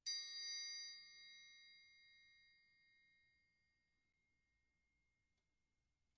VSCO 1 打击乐库 金属 " 锣（锣鼓）击 fff
标签： 金属 打击乐器 vsco- 2 单票据 多重采样
声道立体声